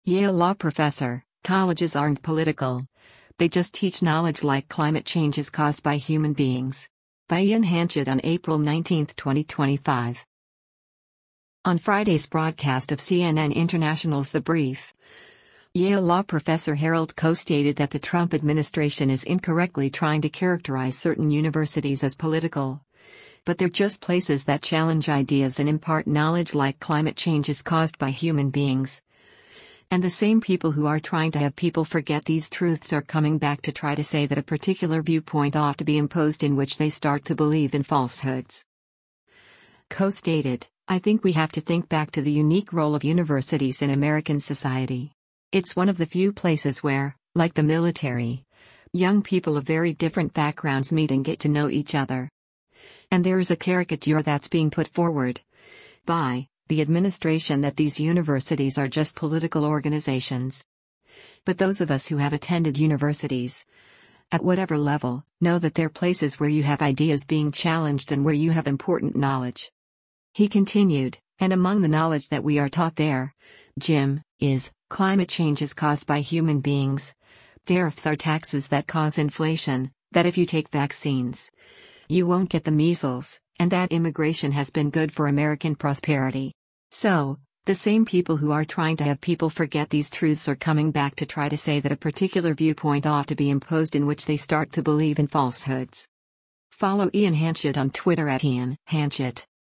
On Friday’s broadcast of CNN International’s “The Brief,” Yale Law Professor Harold Koh stated that the Trump administration is incorrectly trying to characterize certain universities as political, but they’re just places that challenge ideas and impart knowledge like “climate change is caused by human beings,” and “the same people who are trying to have people forget these truths are coming back to try to say that a particular viewpoint ought to be imposed in which they start to believe in falsehoods.”